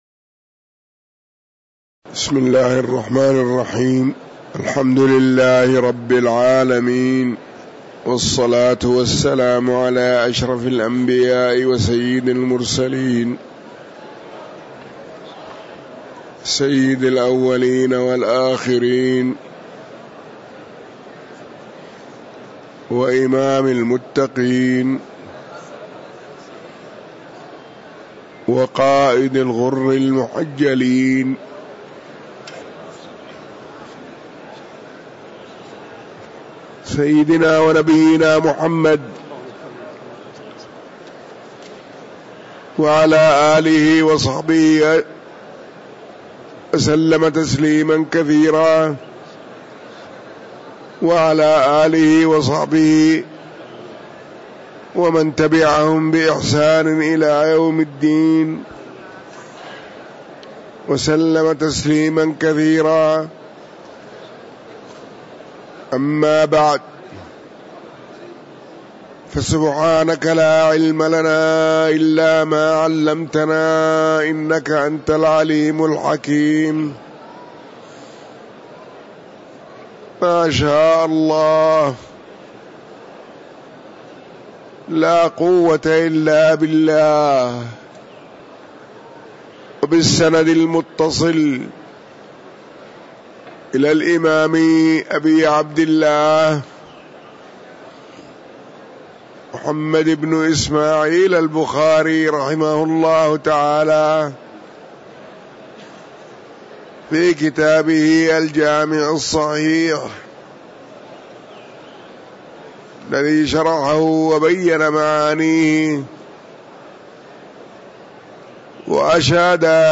تاريخ النشر ١٧ ربيع الأول ١٤٤٥ هـ المكان: المسجد النبوي الشيخ